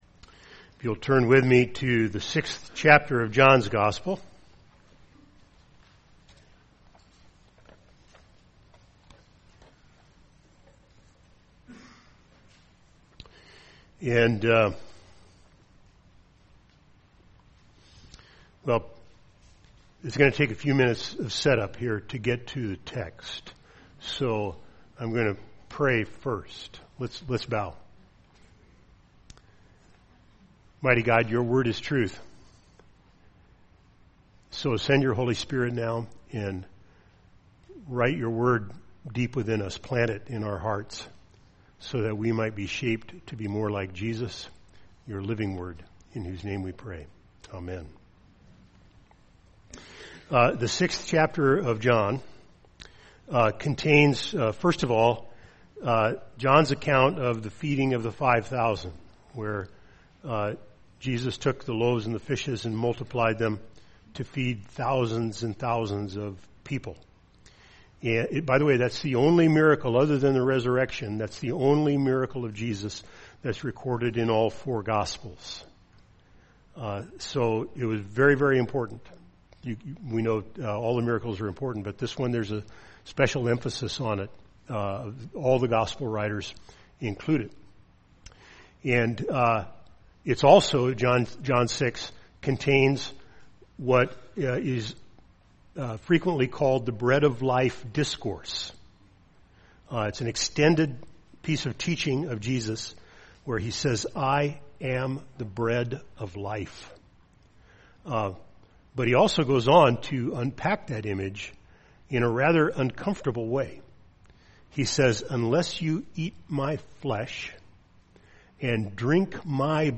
While the title would lead you to believe this is a re-run from last week, this is actually the sermon that goes with the title.